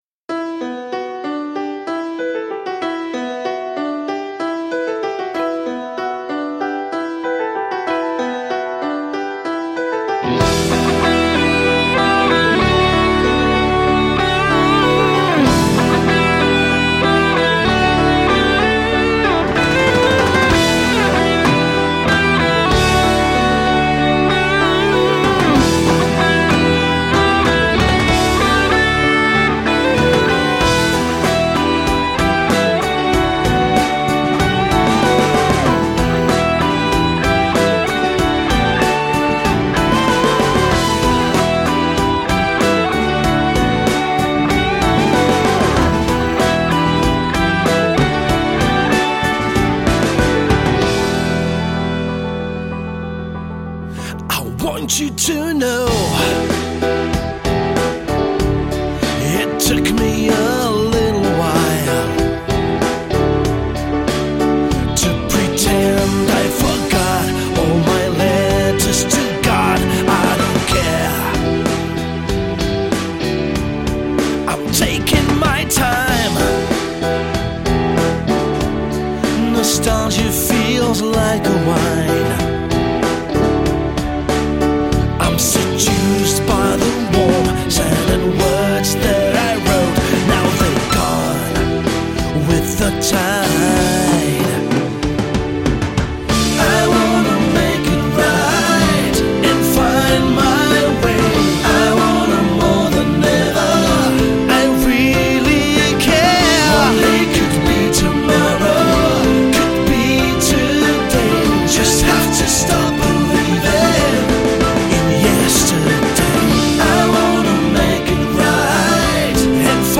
Polish neo-prog band
which seems to aim at a more mainstream, commercial sound.
Just the competent and very appealing kind.